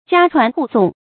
家傳戶頌 注音： ㄐㄧㄚ ㄔㄨㄢˊ ㄏㄨˋ ㄙㄨㄙˋ 讀音讀法： 意思解釋： 見「家傳戶誦」。